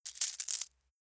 charging-coins.wav